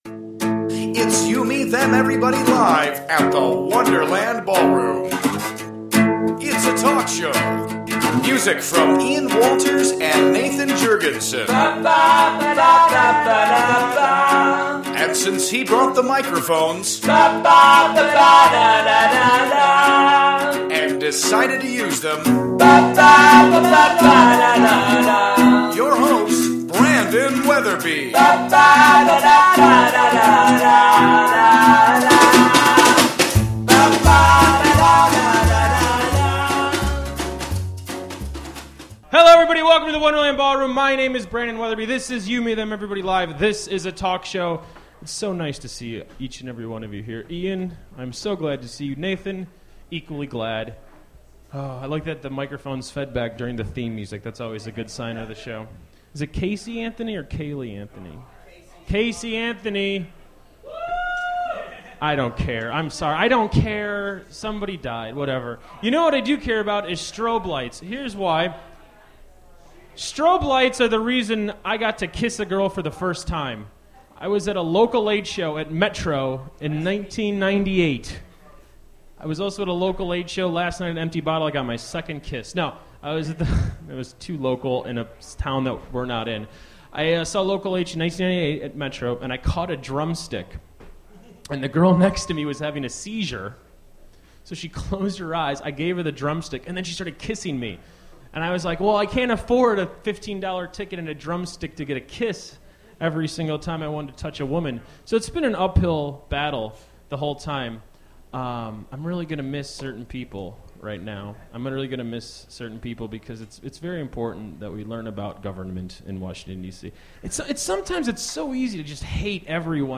The Dustys close the show with a very nice set of very nice music.